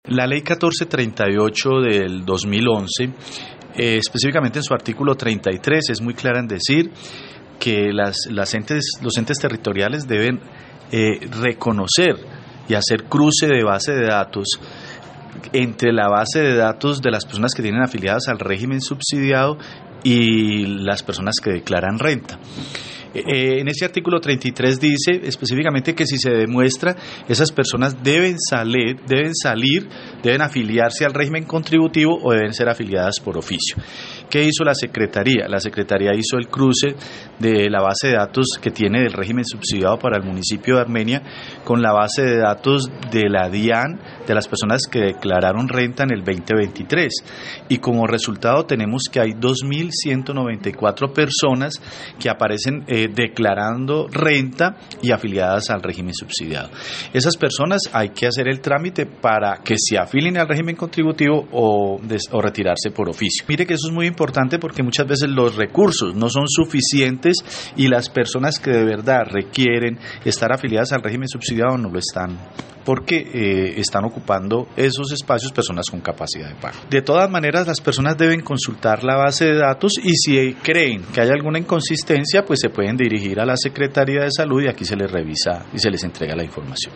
Secretario de Salud de Armenia